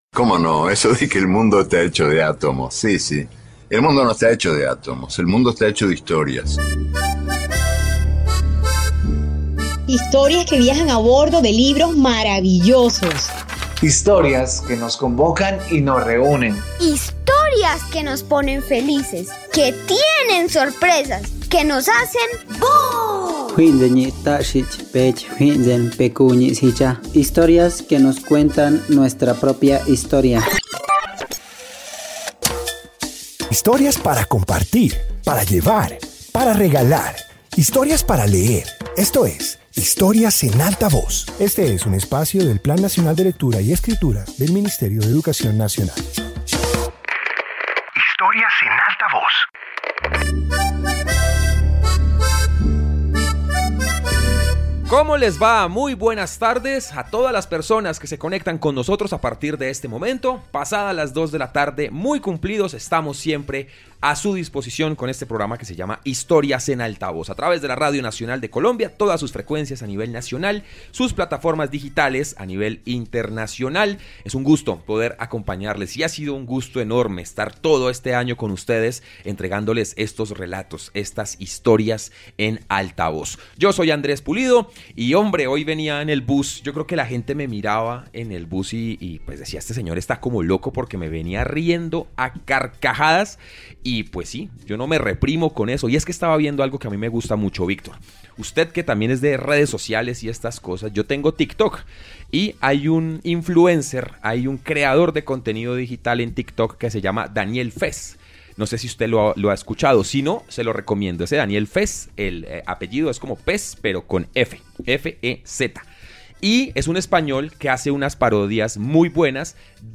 Introducción Este episodio de radio comparte relatos cargados de ingenio y situaciones divertidas. Presenta historias que muestran cómo la risa y el juego verbal hacen parte del mundo literario.